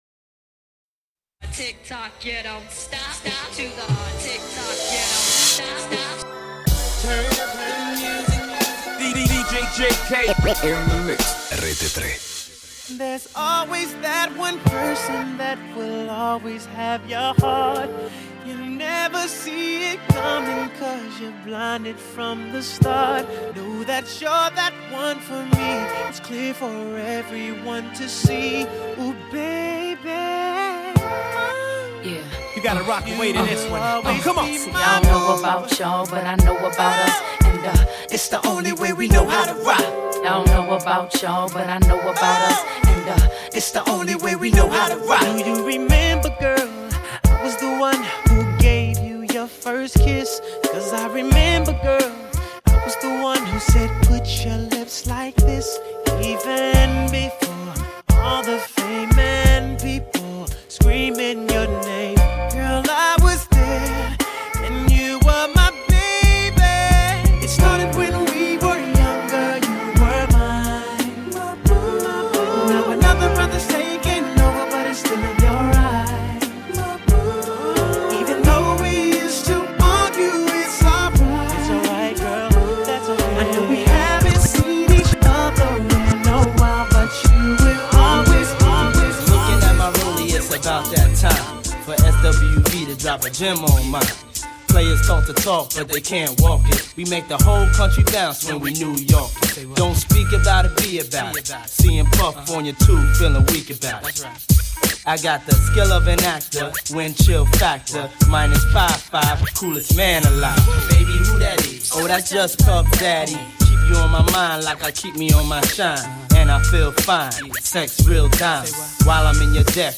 RAP / HIP-HOP BLACK